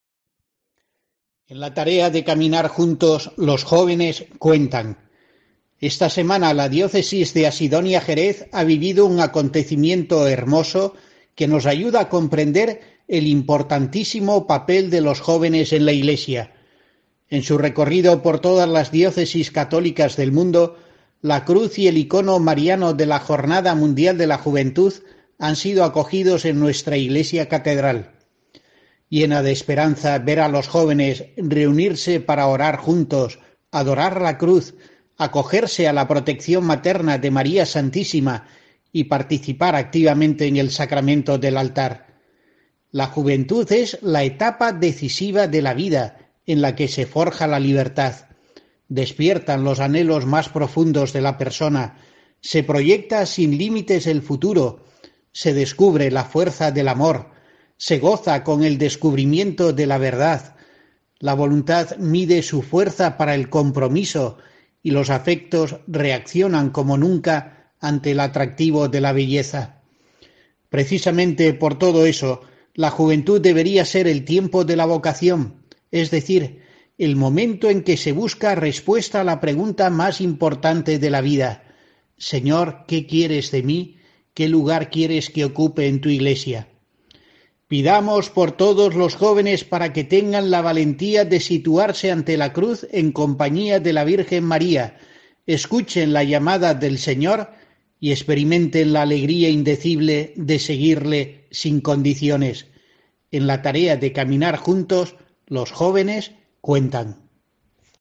Escucha la reflexión pastoral semanal de monseñor José Rico Pavés, obispo de Asidonia-Jerez